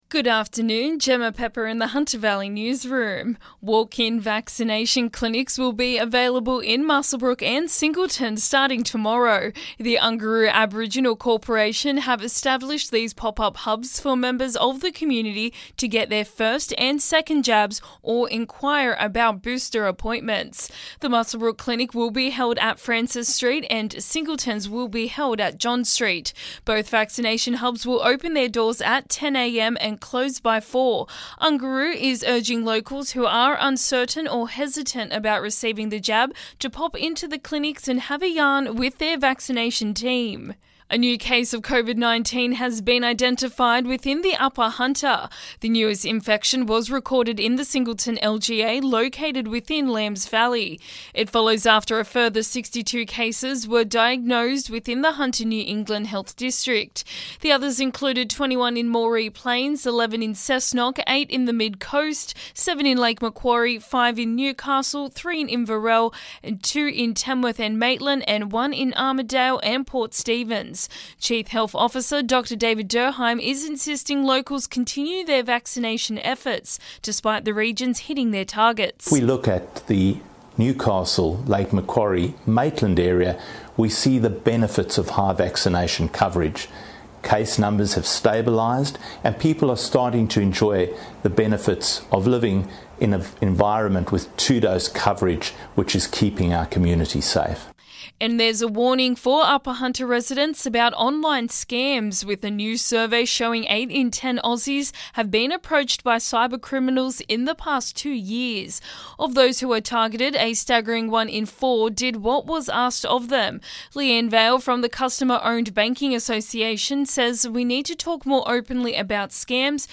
LISTEN: Hunter Valley Local News Headlines 9/11/2021